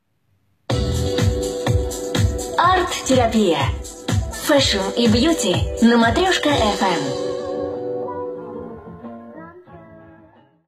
Indicatiu d'un programa dedicat a la moda i la bellesa